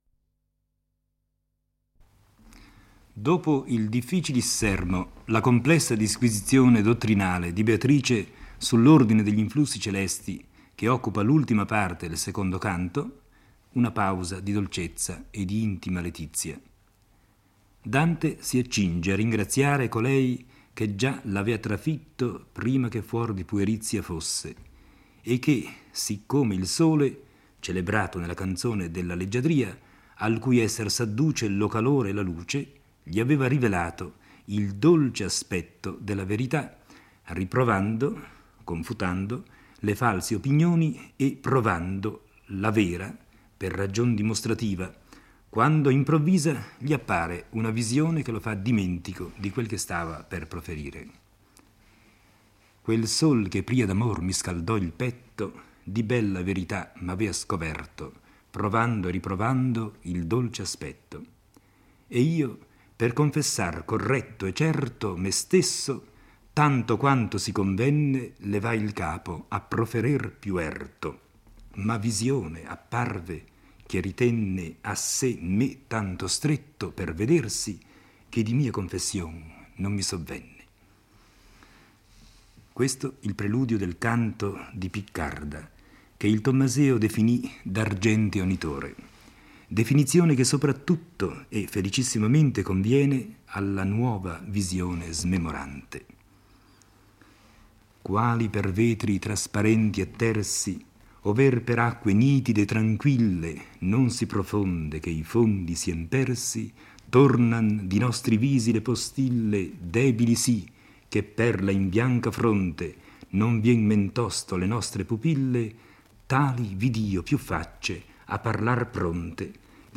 legge e commenta il III canto del Paradiso. Dopo le spiegazioni appena ricevute da Beatrice, Dante è attratto da una visione: vede molti volti indistinti pronti a parlare e credendo trattarsi di immagini riflesse situate alle sue spalle, si volge indietro. Dante si rivolge all'ombra più desiderosa di parlare e le chiede di rivelare il suo nome e la condizione delle anime che si trovano con lei.